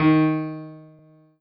piano-ff-31.wav